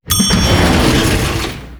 Apertura automática de la puerta de un ascensor
ascensor
puerta